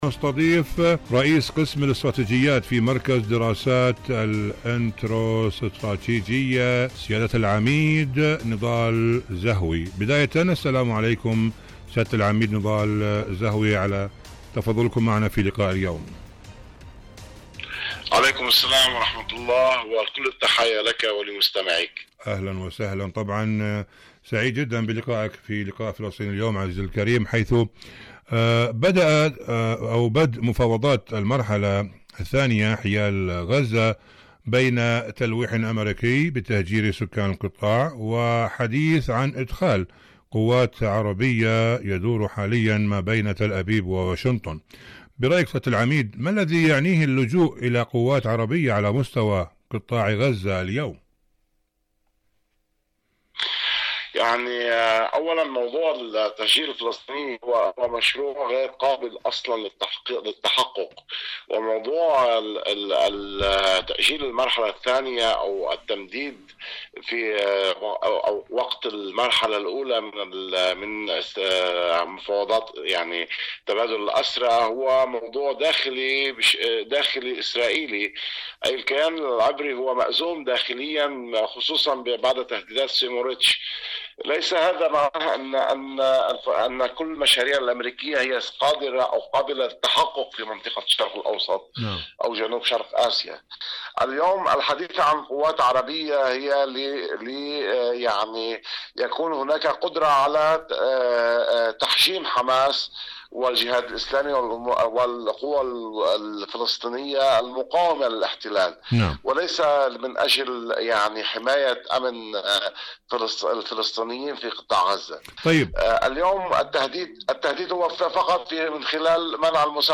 برنامج فلسطين اليوم مقابلات إذاعية